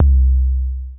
DC808Kikbass.wav